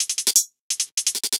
Index of /musicradar/ultimate-hihat-samples/170bpm
UHH_ElectroHatB_170-05.wav